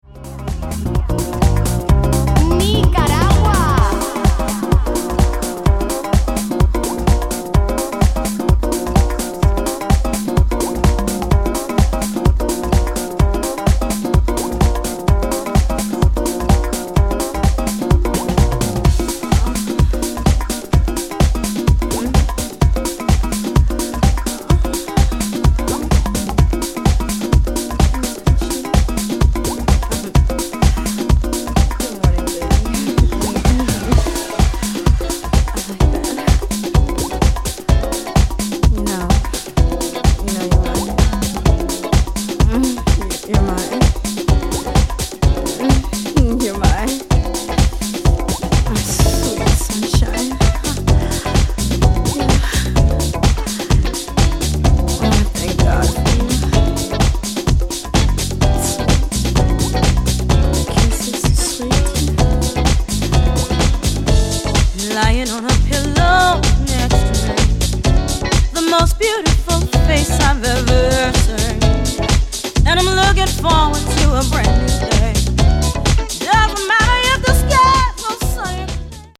Here’s how a transition like this sounds in real life:
Hear how the track smoothly blends into the next one?
what_is_mixing-blend.mp3